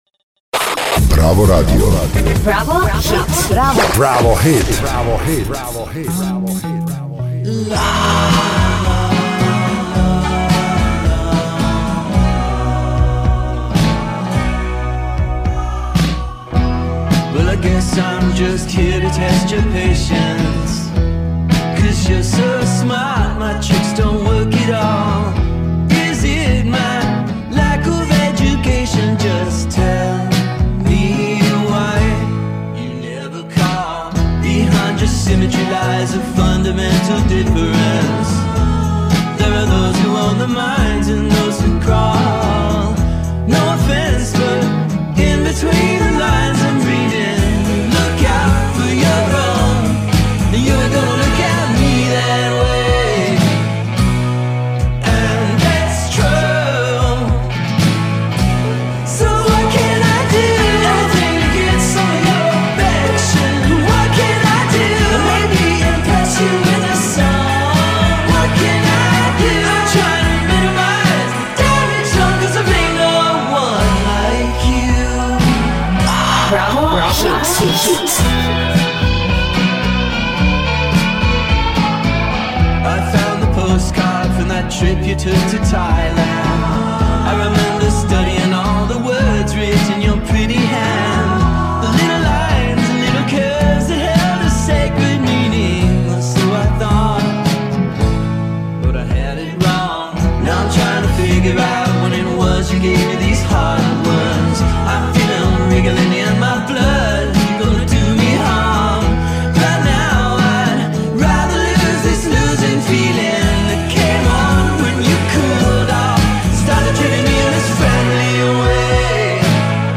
американската рок група